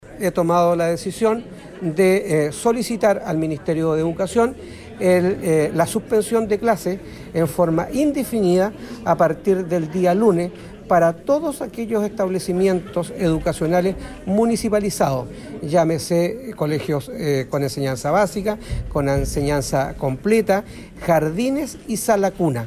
Así lo indicó el alcalde de la comuna Carlos Gómez.